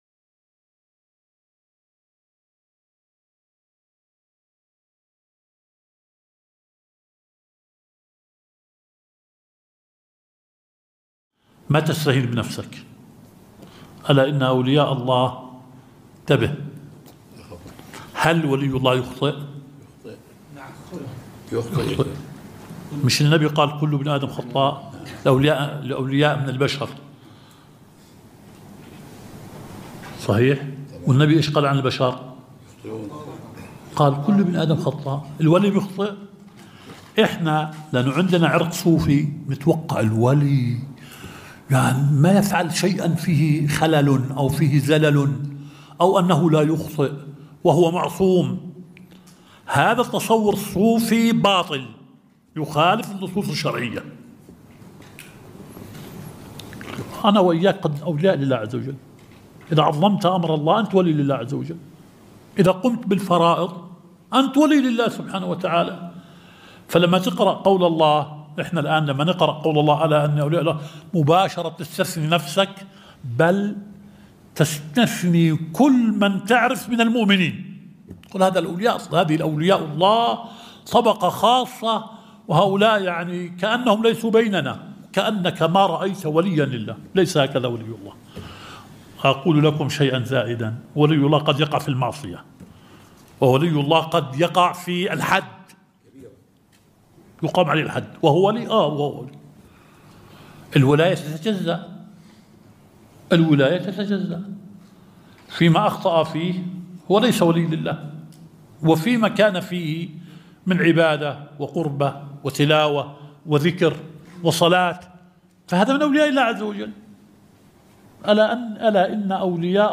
الدرس السابع – شرح مبحث العام والخاص في أصول الفقه